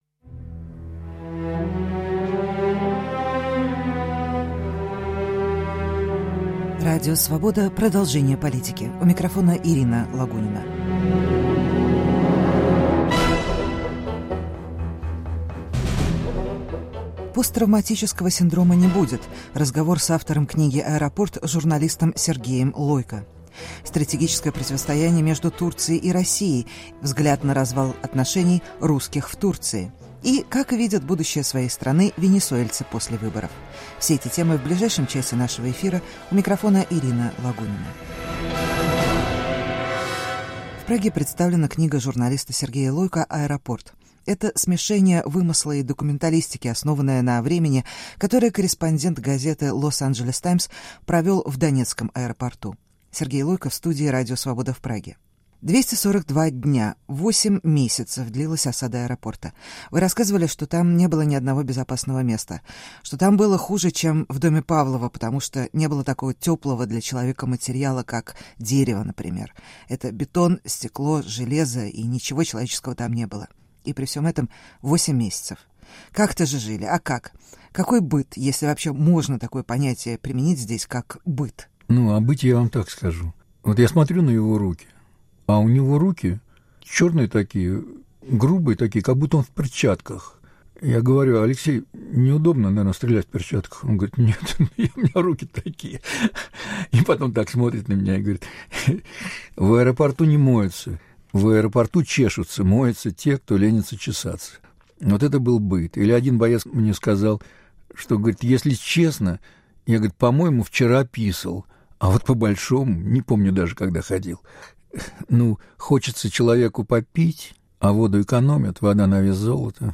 Разговор с автором книги "Аэропорт", журналистом Сергеем Лойко. *** Противоречия между Турцией и Россией - стратегические.